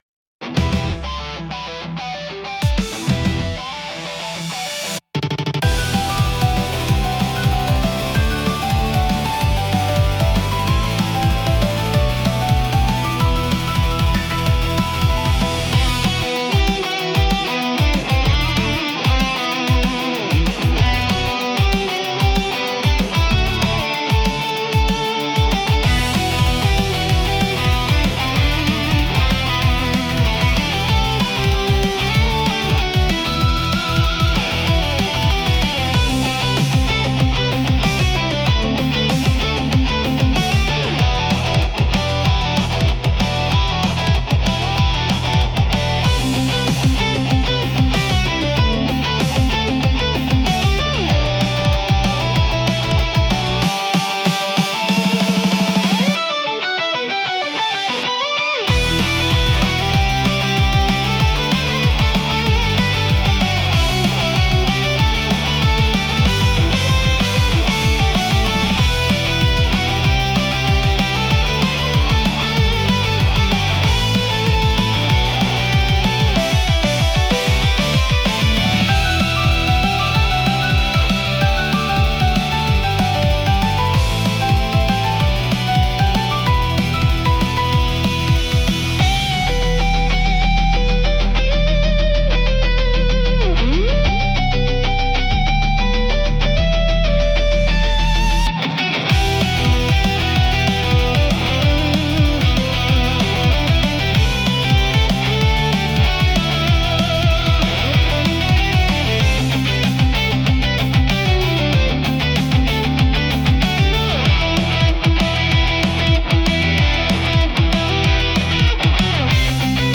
公開している音楽は、映画やアニメ、ゲームに想定したBGMや、作業用のBGMを意識して制作しています。